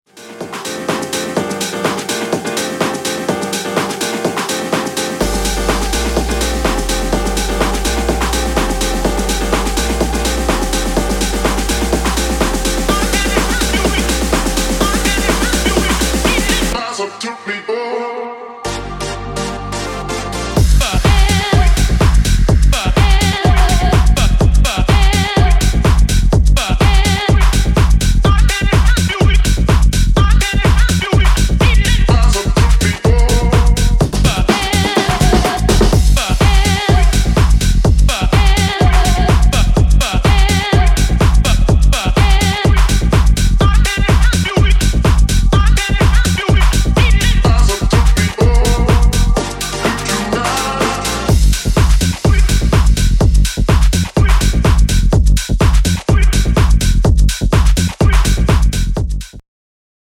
House, Tech House y Techno más bailable